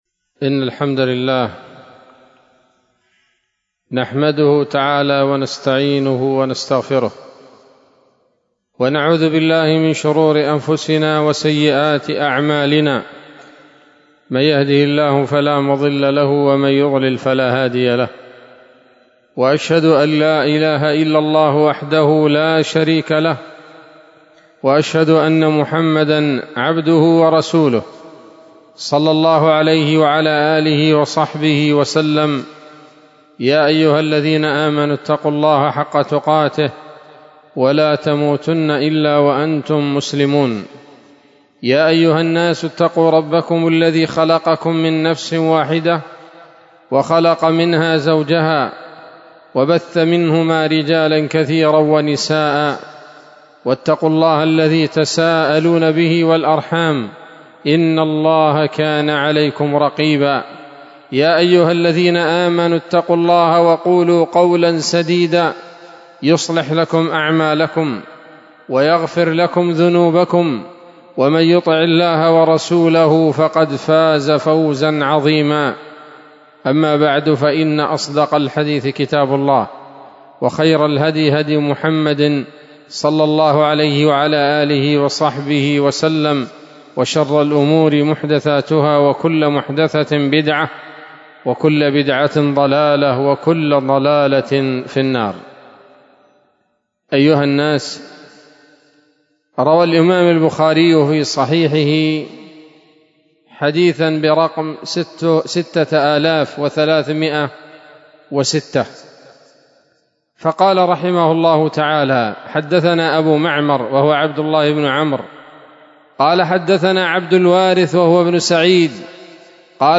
خطبة جمعة بعنوان: (( الاعتبار بما في حديث سيد الاستغفار )) 13 جمادى الأولى 1446 هـ، دار الحديث السلفية بصلاح الدين